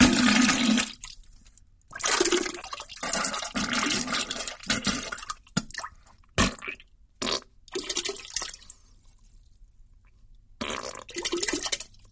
Diarrhea attack